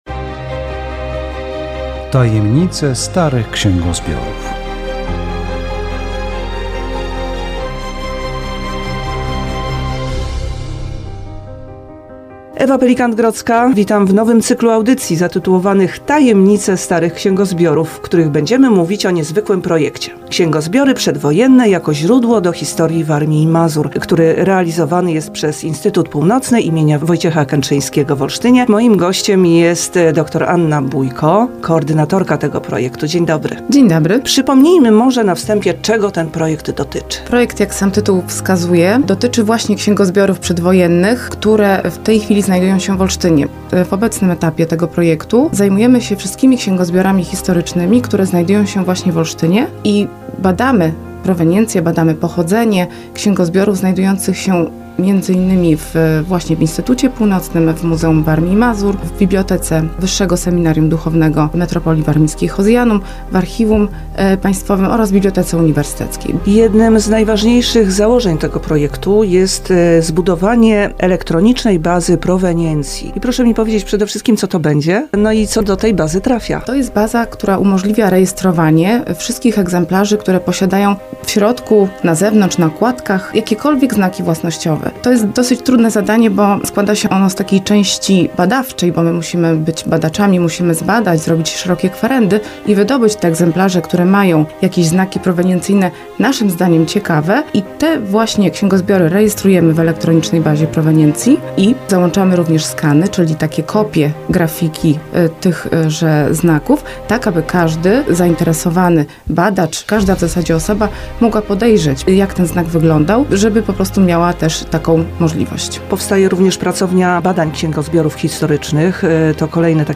Audycja radiowa "Tajemnice starych księgozbiorów"